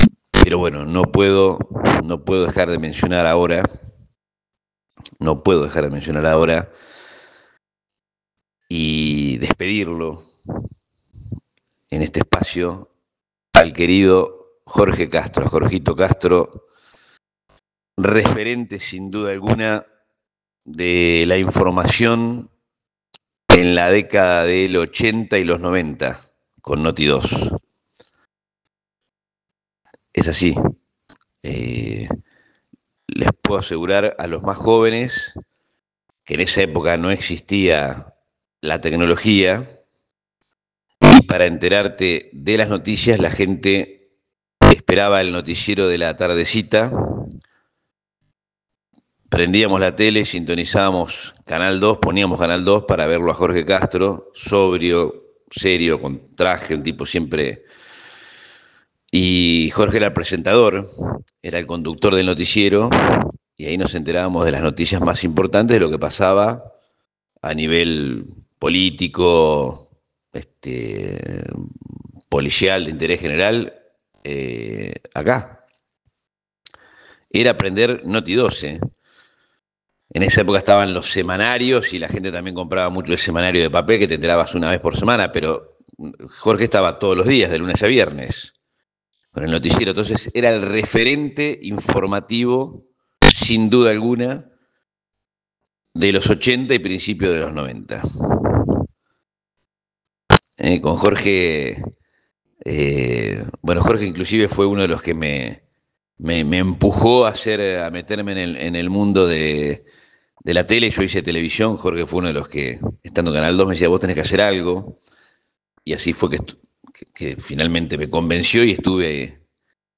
Audio Editorial en el programa periodístico Magazine Café por FM 104.1